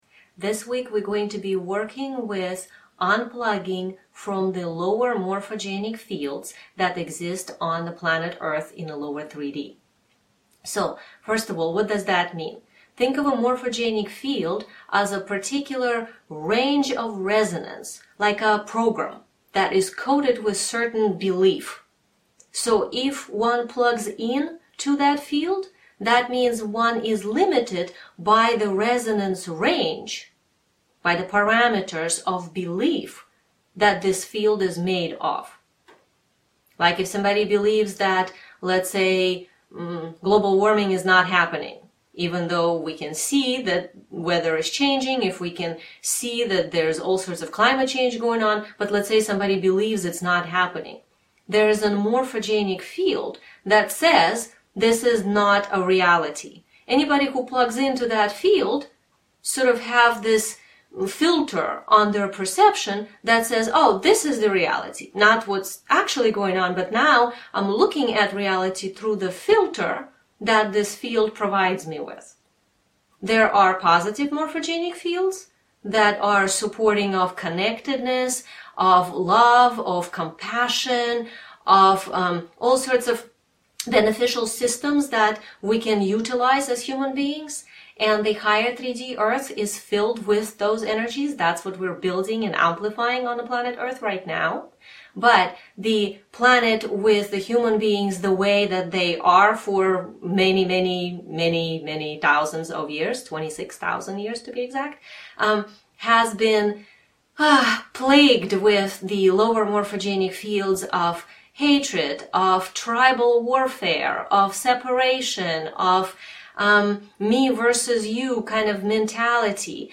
2- DOWNLOAD the AUDIO into your phone/computer so that you can listen to this meditation again and PRACTICE;